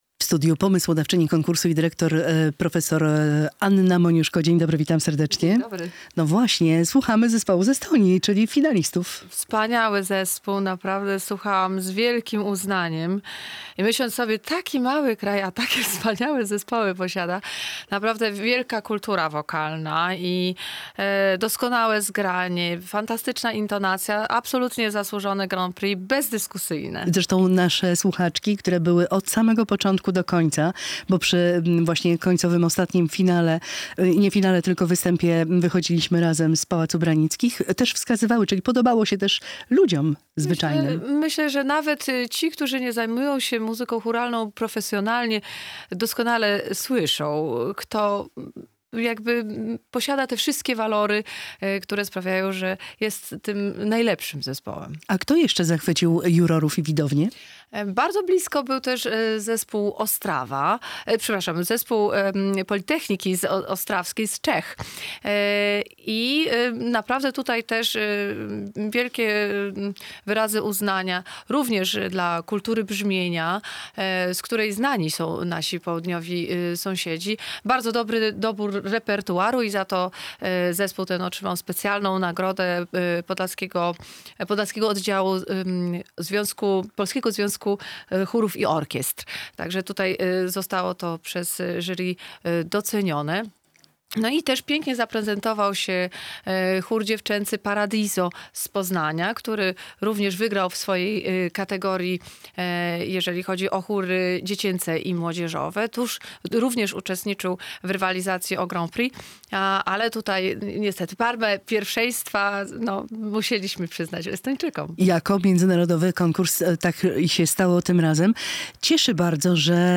rozmawia